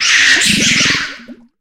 Fichier:Cri 1015 HOME.ogg — Poképédia
Cri de Fortusimia dans Pokémon HOME.